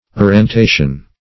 Search Result for " arrentation" : The Collaborative International Dictionary of English v.0.48: Arrentation \Ar`ren*ta"tion\ [Cf. F. arrenter to give or take as rent.